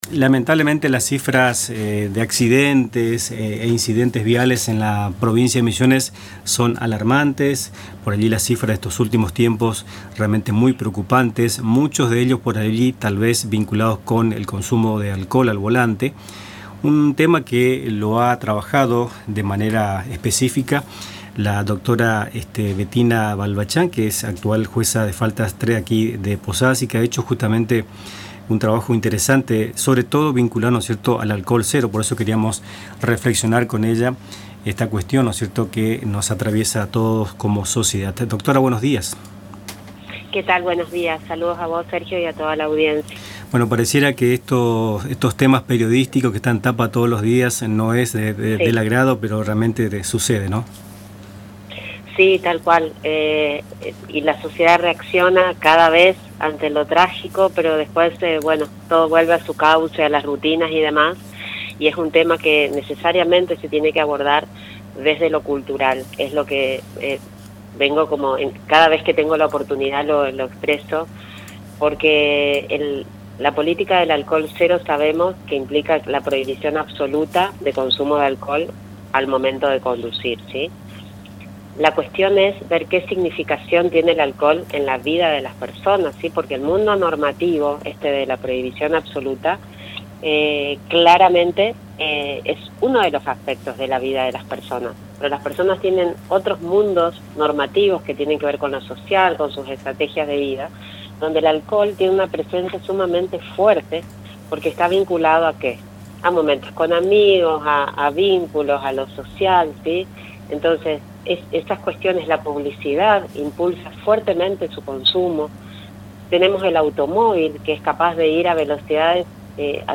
Para analizar esta problemática, Nuestras Mañanas entrevistó a la Dra. Bettina Balbachán, jueza del Tribunal de Faltas N º 3 de Posadas y especialista en políticas de alcohol cero.